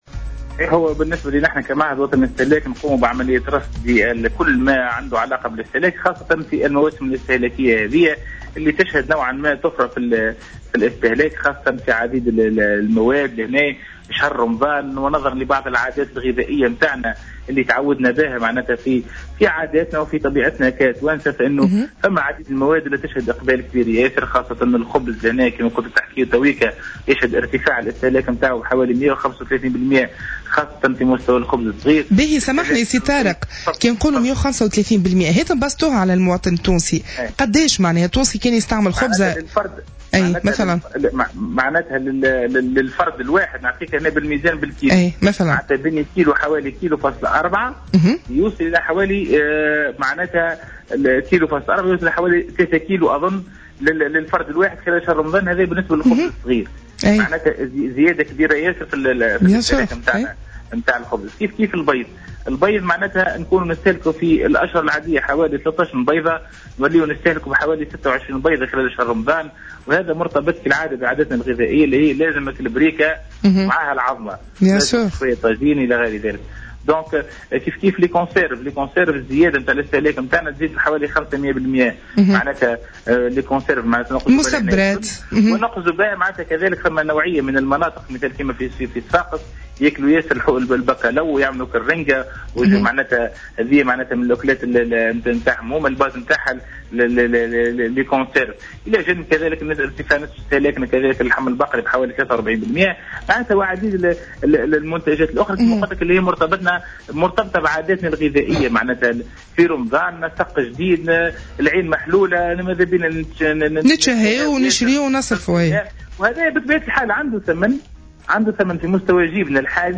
قال مدير عام المعهد الوطني للاستهلاك،طارق بن جازية في مداخلة له اليوم الثلاثاء على "جوهرة أف أم" إن كلفة تبذير الخبز في شهر رمضان تقدّر ب300 ألف دينار يوميا أي مايعادل 100 مليار سنويا.